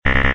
add touch event sounds using libcanberra
error.ogg